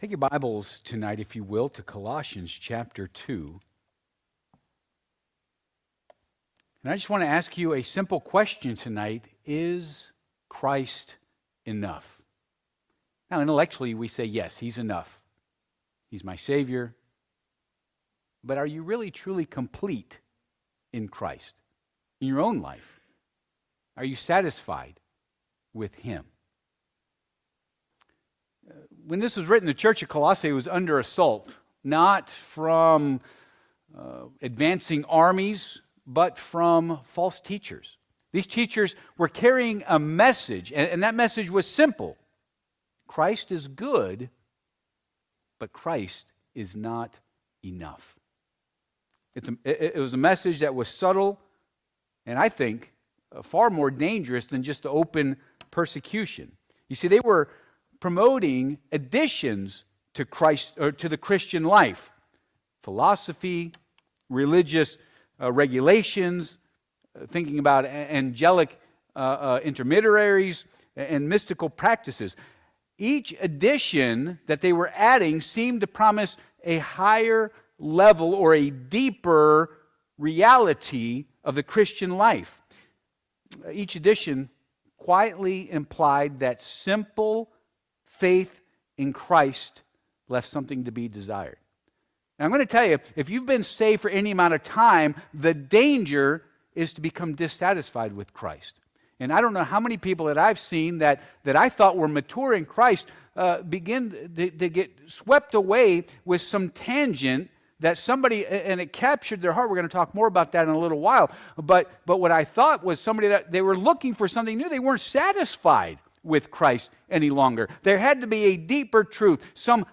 Col. 2:6-10 Service Type: Midweek Service « He is Still a Rewarder of Those that Diligently Seek Him Are You a Sold out Servant?